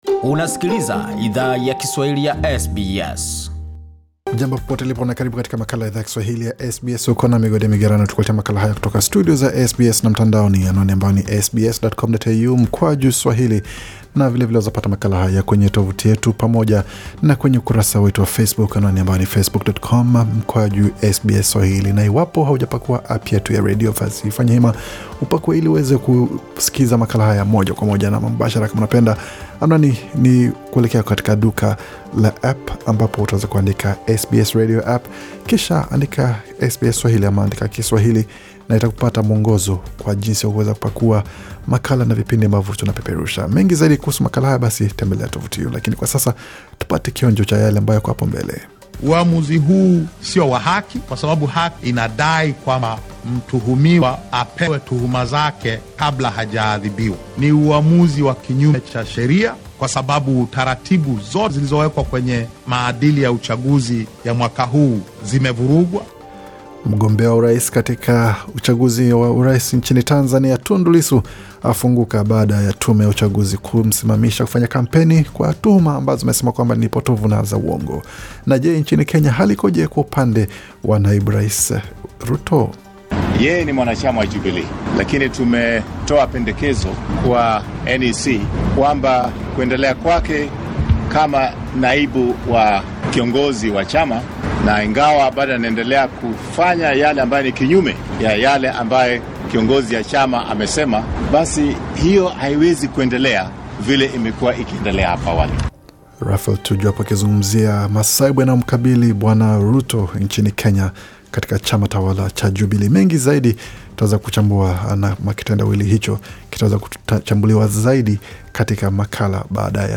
Taarifa ya habari 4 Oktoba 2020